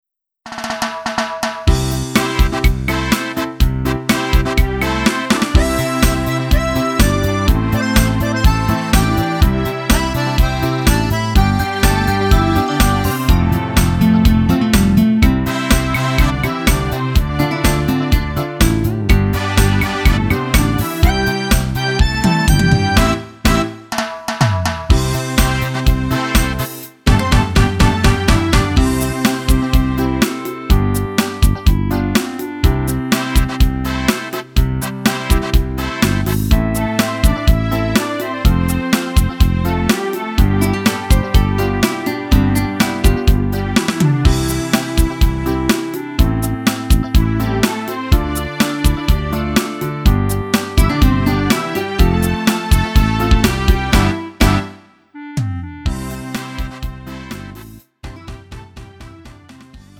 음정 -1키 3:29
장르 가요 구분 Lite MR
Lite MR은 저렴한 가격에 간단한 연습이나 취미용으로 활용할 수 있는 가벼운 반주입니다.